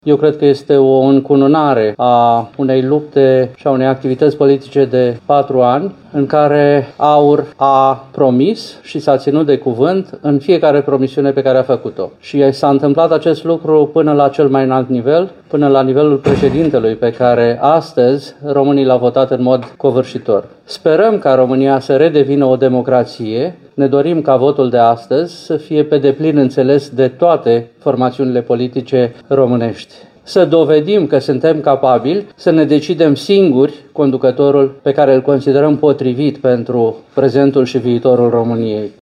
Deputatul FLORIN PUȘCAȘU.